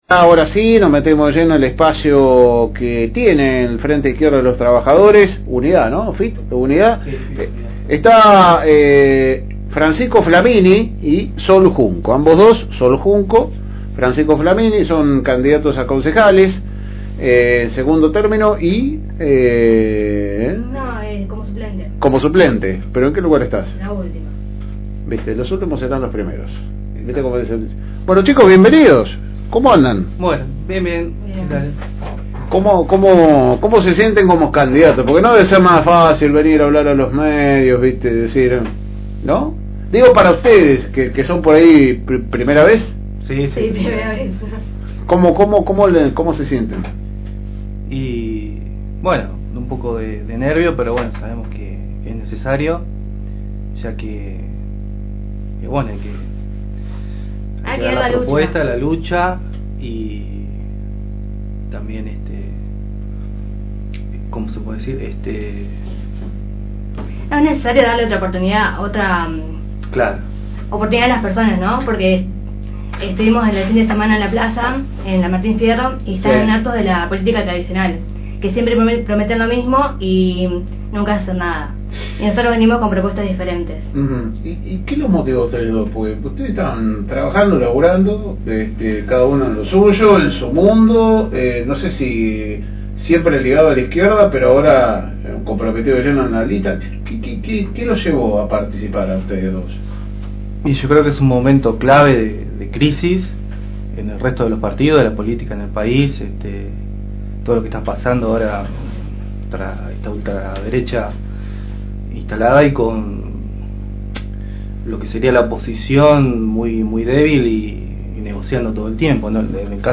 En esta ocasión pasaron por los estudios de la FM Reencuentro dos de los candidatos del Frente de Izquierda y los Trabajadores